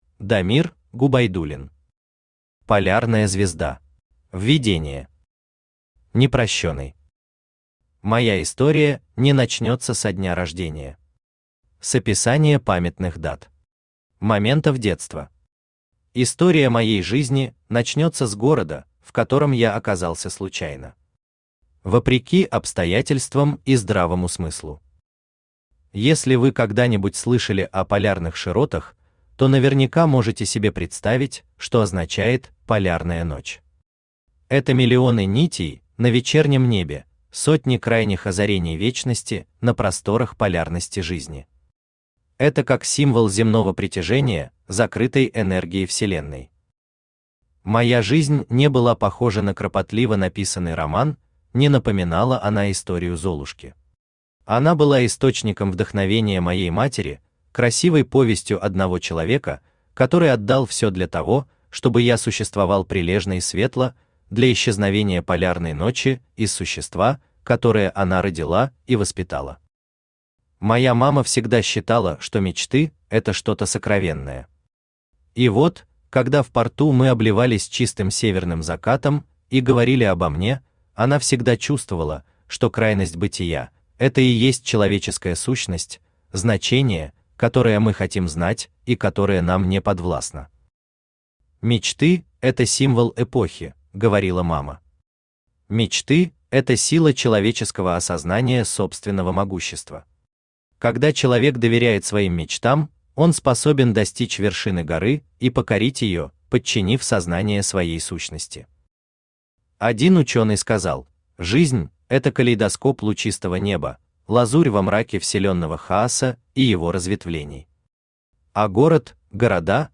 Аудиокнига Полярная звезда | Библиотека аудиокниг
Aудиокнига Полярная звезда Автор Дамир Губайдуллин Читает аудиокнигу Авточтец ЛитРес.